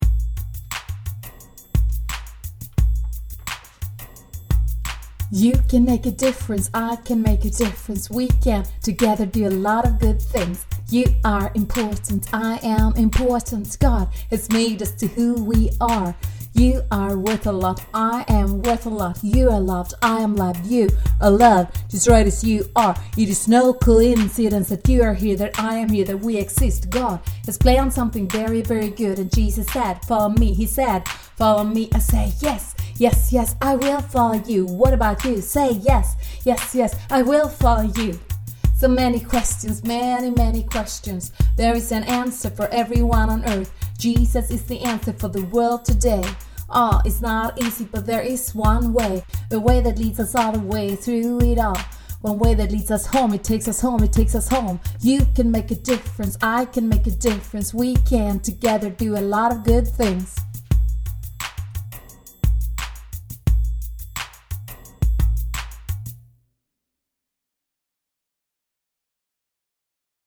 Here is a rap..!!